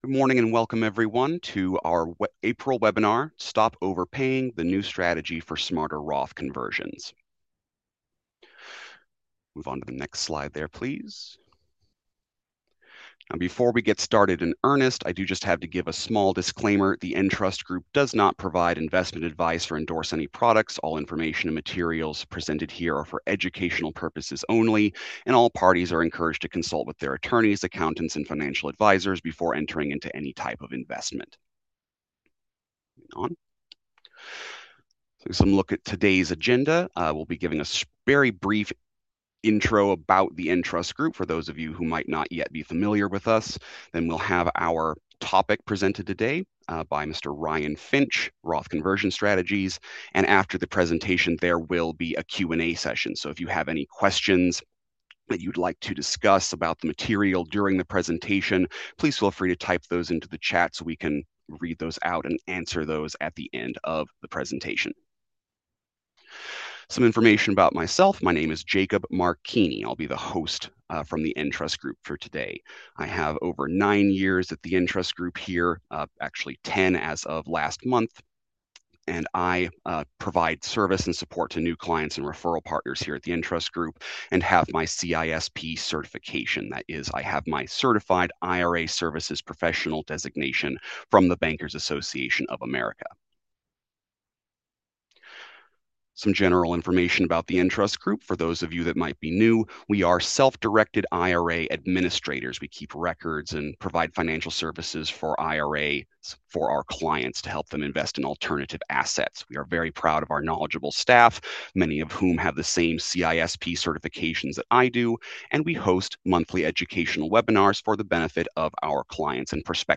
In this educational training, we'll provide a data-driven deep dive into what's trending with real estate in 2026.